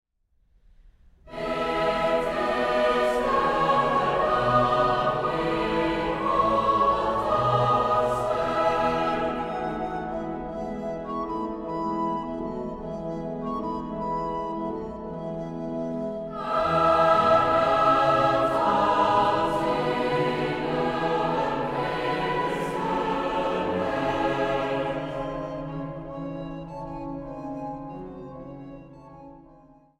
Kerkmuziek door de eeuwen heen
hoofdorgel
koororgel
blokfluit.
Zang | Jongerenkoor